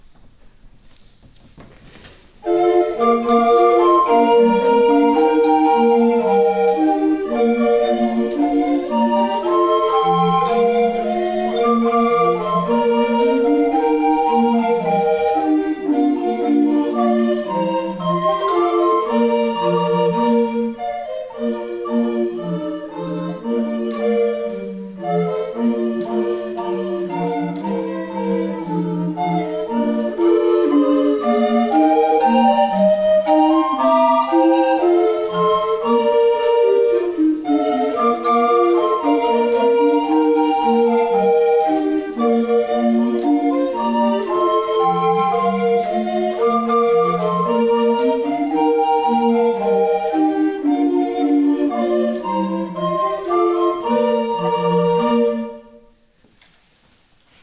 Hier können Sie gern einige Klangeindrücke unseres Ensembles gewinnen: